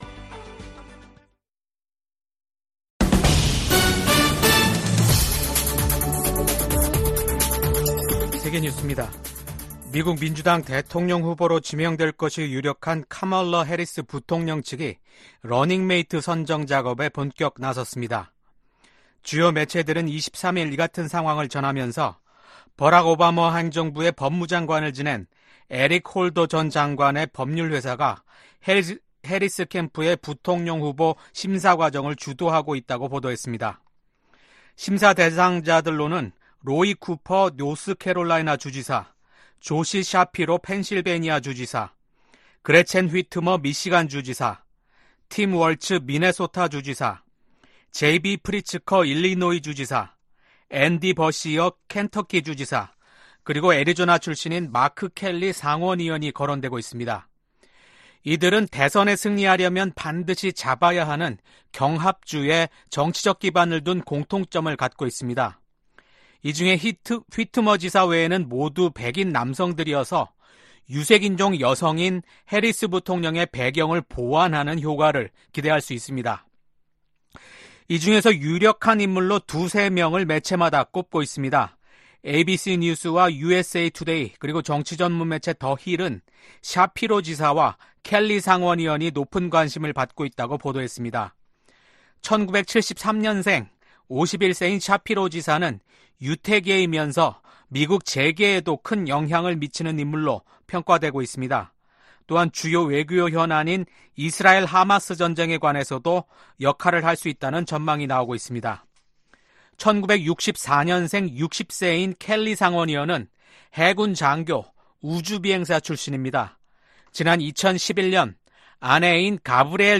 VOA 한국어 아침 뉴스 프로그램 '워싱턴 뉴스 광장' 2024년 7월 25일 방송입니다. 북한이 또 다시 쓰레기 풍선을 한국 쪽에 날려보냈습니다.